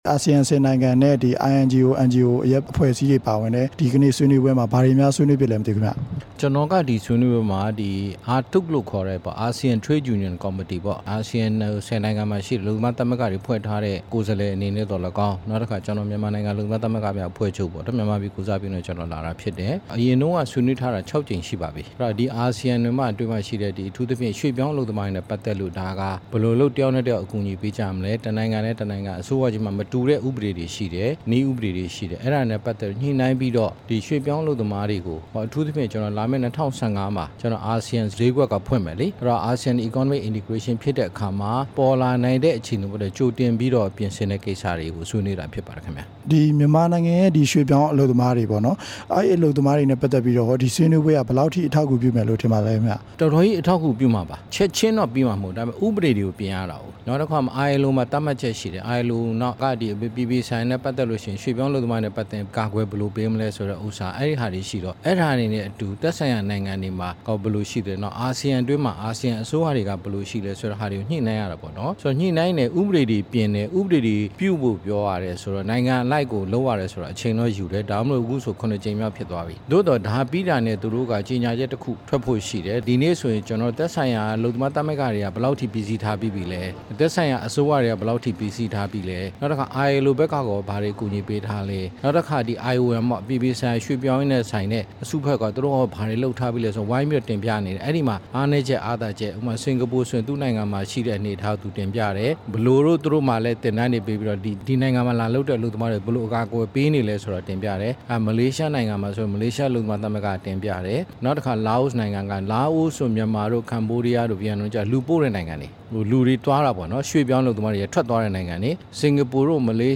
ရွှေ့ပြောင်းအလုပ်သမားများဆိုင်ရာ အာဆီယံအစည်းအဝေးအကြောင်း မေးမြန်းချက်